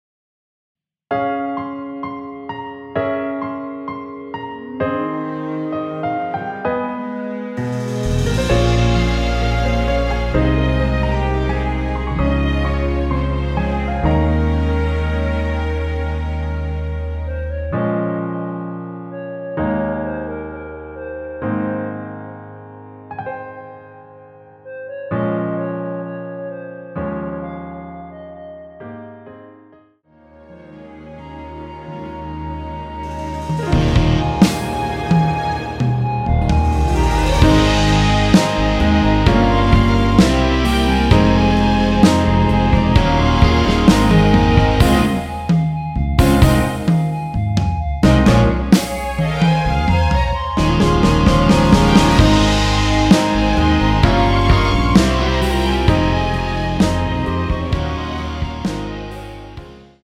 원키에서(-2)내린 멜로디 포함된 MR입니다.(미리듣기 확인)
Ab
앞부분30초, 뒷부분30초씩 편집해서 올려 드리고 있습니다.